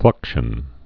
(flŭkshən)